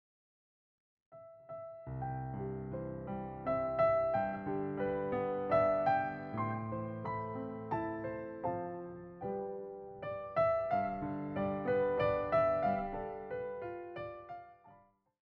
all given a solo piano treatment.